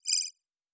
pgs/Assets/Audio/Sci-Fi Sounds/Interface/Data 26.wav at master